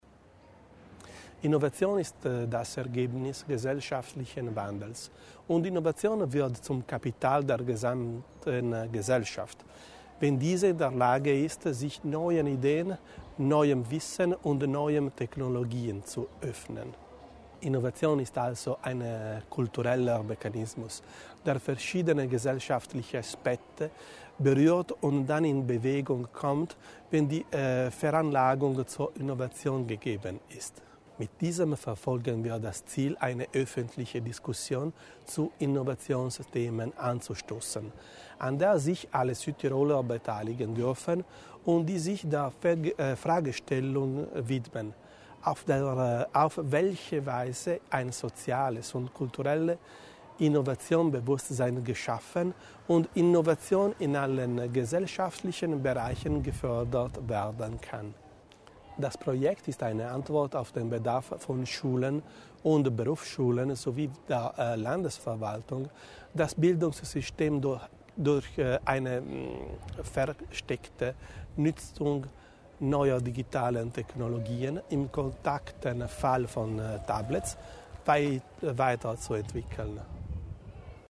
Landesrat Bizzo über die Wichtigkeit der Innovationsgespräche